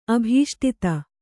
♪ abhīṣṭita